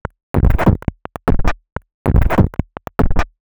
tx_perc_140_grundle2.wav